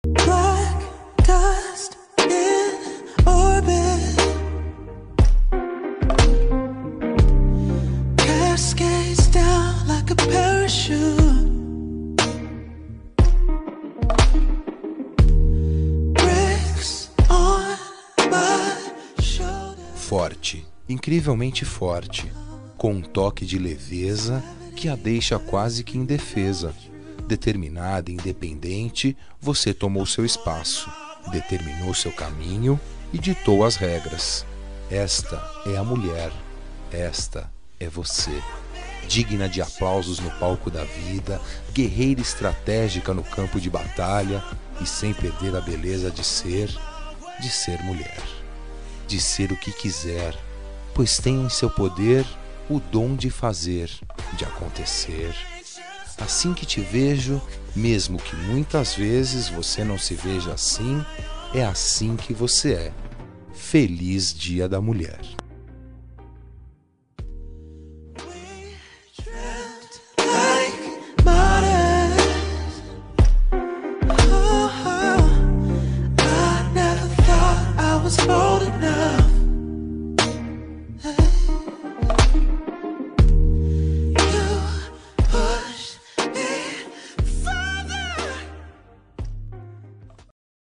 Dia das Mulheres Neutra – Voz Masculina – Cód: 5276